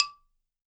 52-prc11-bala-d4.wav